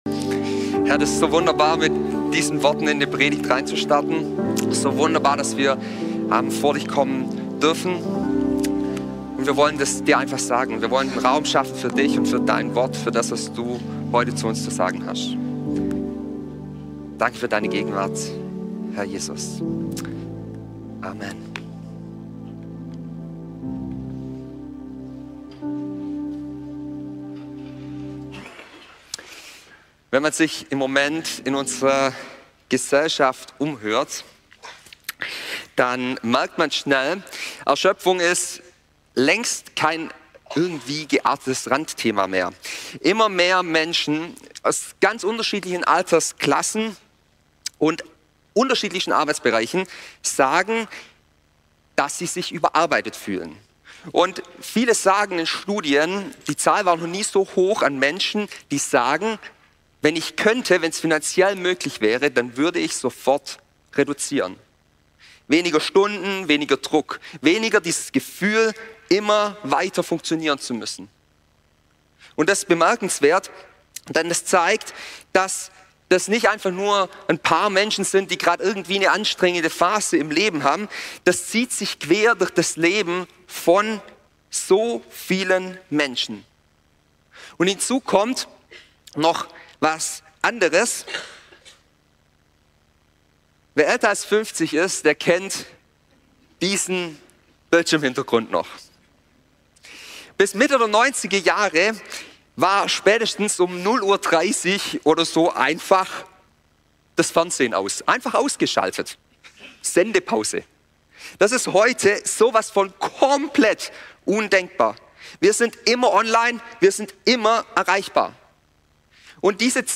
Typ: Predigt